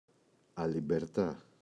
αλιμπερτά, η [alibeꞋrta]